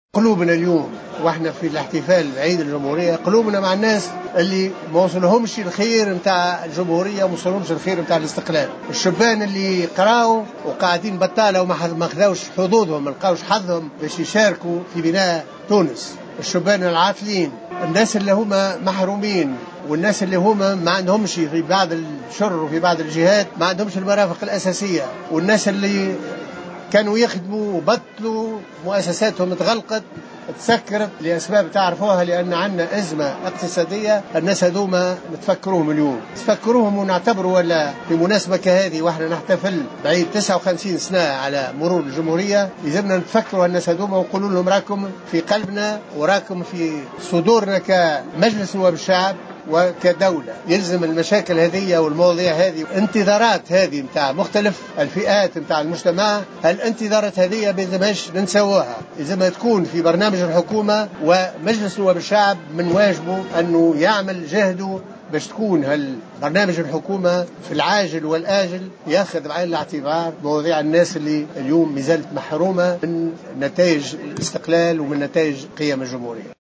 قال رئيس مجلس نواب الشعب، محمد الناصر اليوم خلال موكب انتظم بقصر باردو لإحياء الذكرى 59 لعيد الجمهورية إن قيم الجمهورية تكرست في دستور الجمهورية الثانية و"نحن حريصون على تجسيمها".